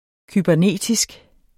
Udtale [ kybæɐ̯ˈneˀtisg ]